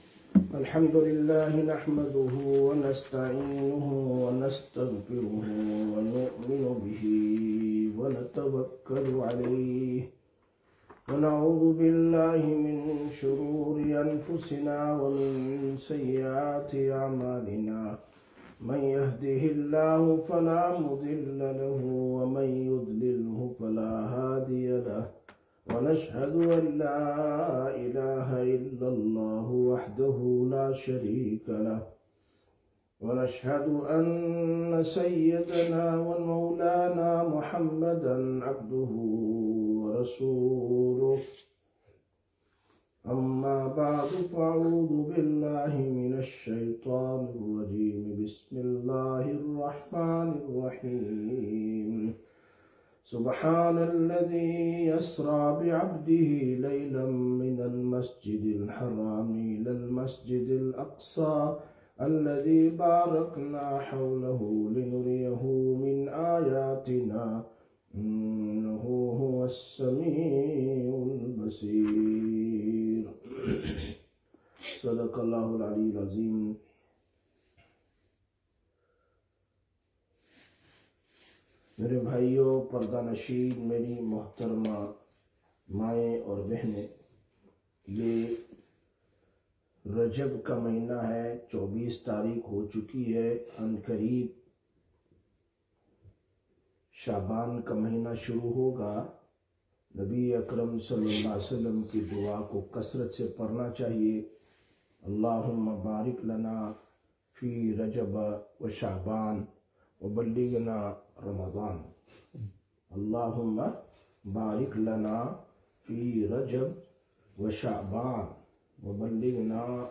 14/01/2026 Sisters Bayan, Masjid Quba